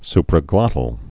(sprə-glŏtl)